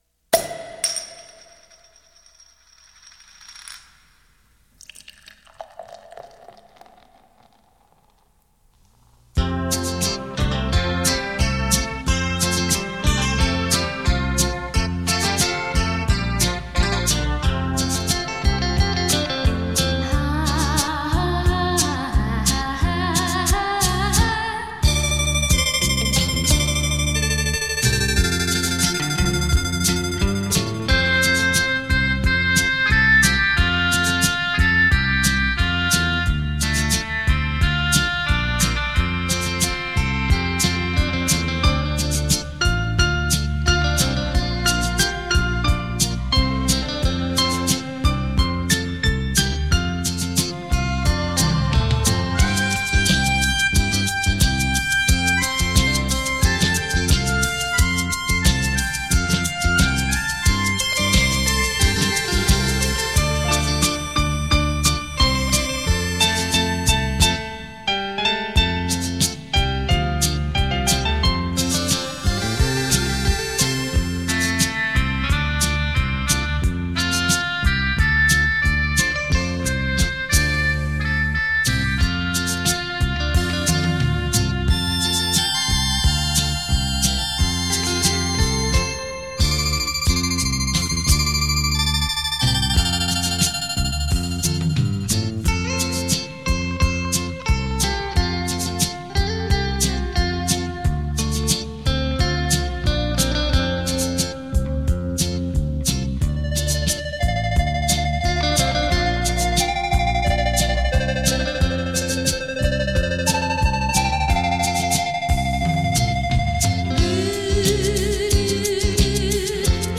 伦巴